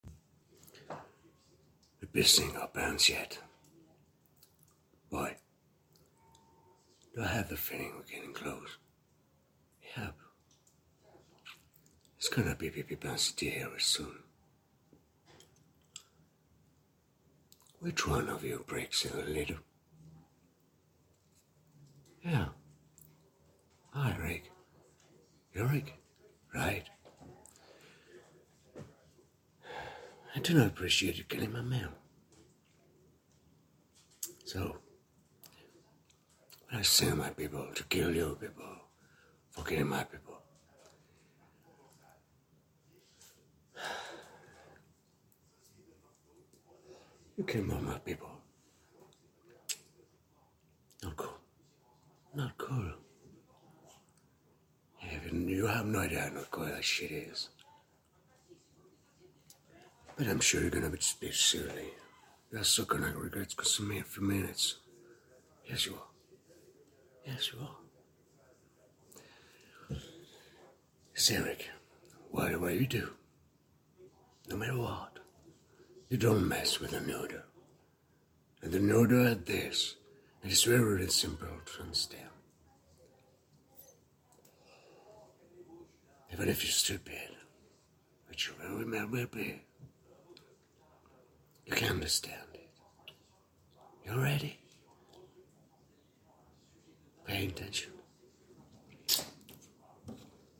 Comédien
- Contre-ténor